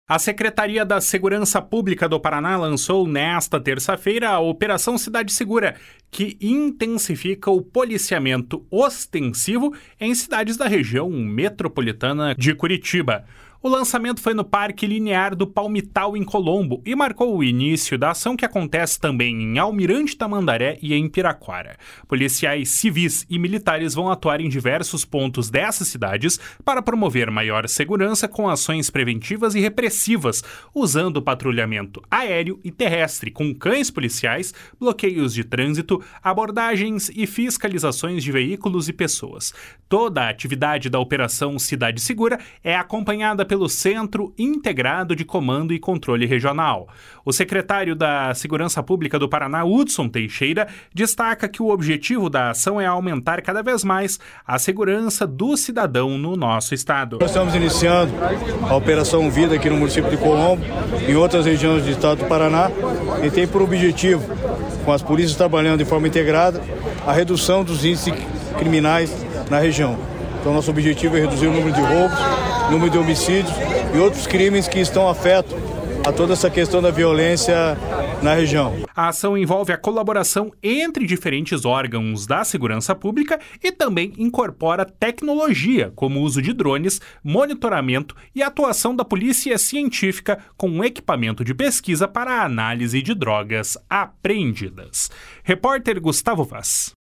O secretário da Segurança Pública, Hudson Teixeira, destaca que o objetivo da ação é aumentar, cada vez mais, a segurança do cidadão paranaense. // SONORA HUDSON TEIXEIRA //